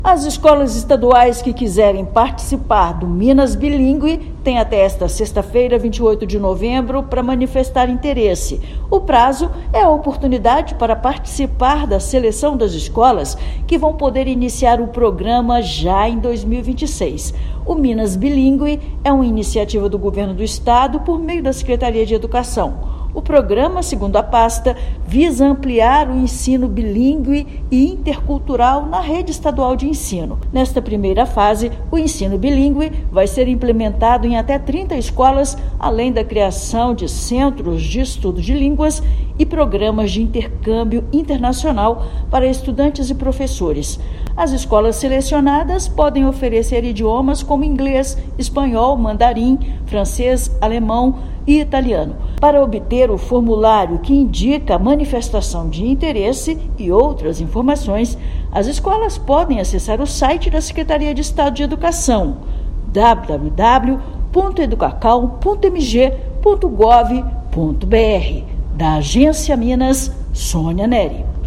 Programa prevê ensino de diversas línguas, centros de estudo e intercâmbios internacionais a partir de 2026. Ouça matéria de rádio.